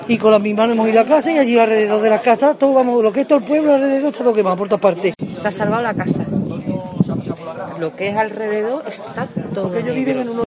Testimonios vecinas desalojadas